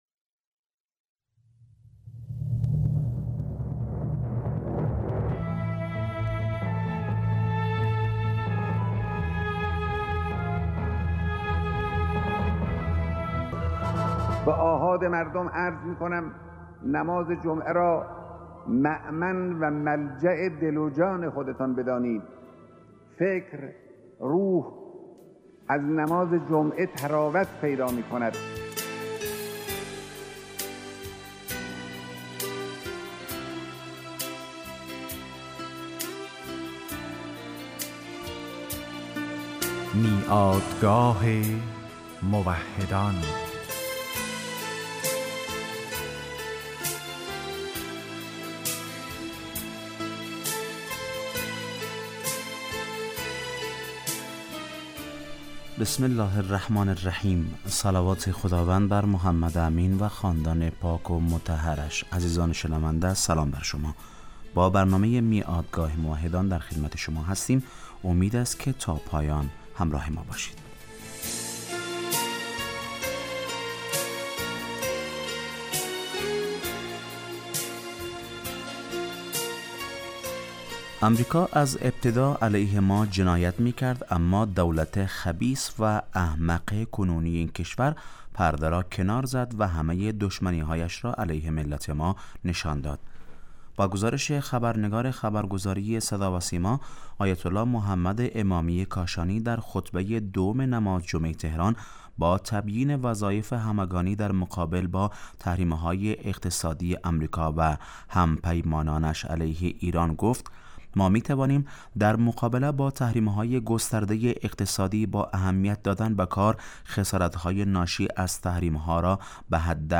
آیت الله محمد امامی کاشانی در خطبه دوم نماز جمعه تهران با تبیین وظایف همگانی در مقابل با تحریم های اقتصادی امریکا و هم پیمانانش علیه کشورمان گفت : ما می تو...